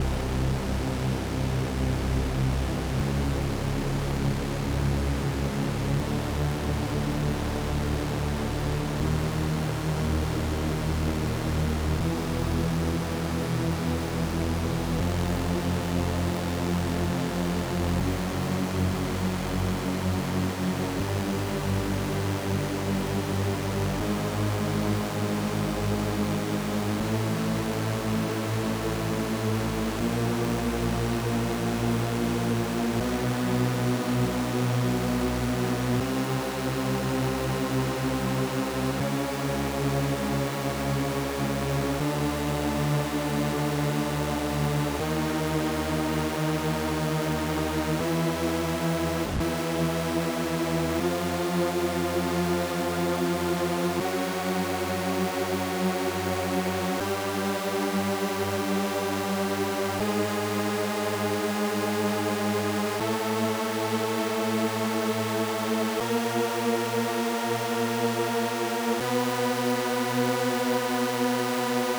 M SuperSaw1.wav